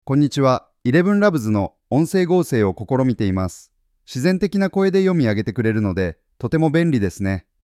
音声サンプル：基本的な日本語読み上げ